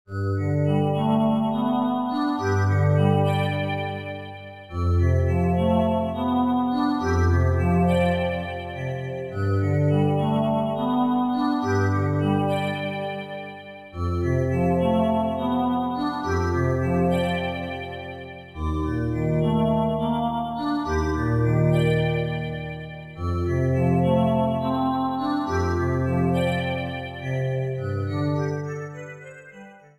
Shortened, applied fade-out, and converted to oga